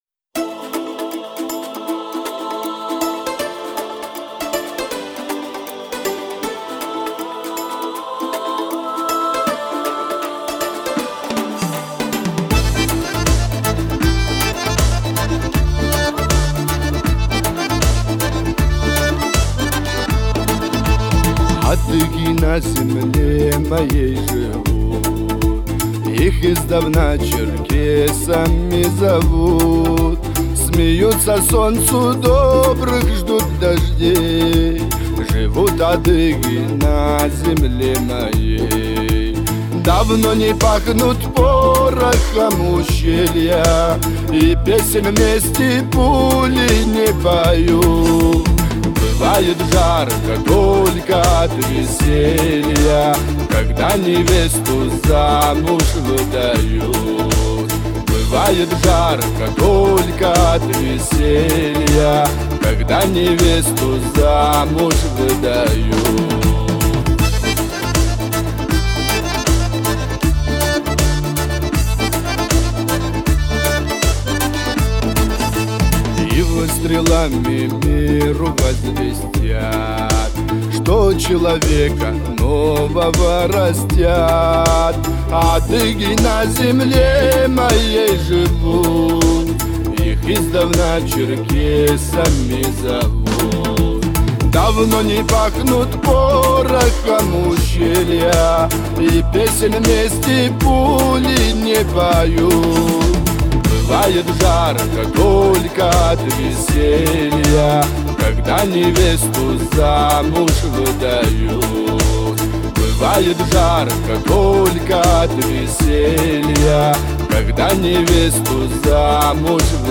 Адыгская музыка